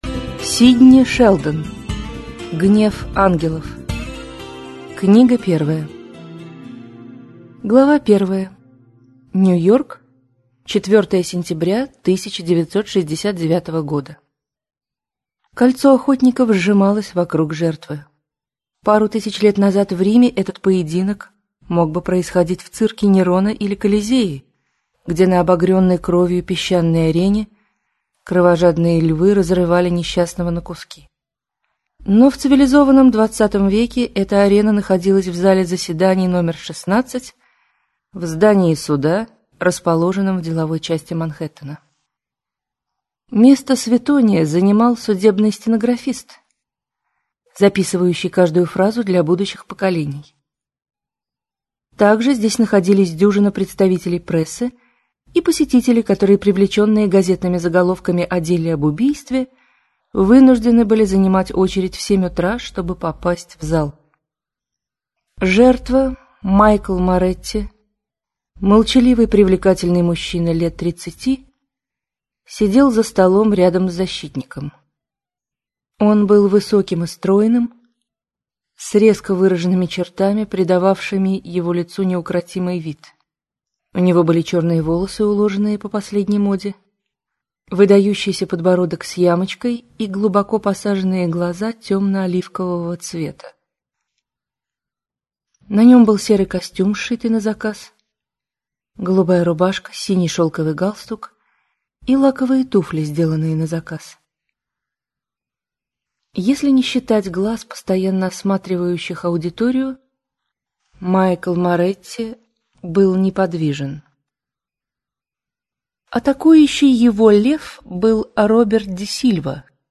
Аудиокнига Гнев ангелов - купить, скачать и слушать онлайн | КнигоПоиск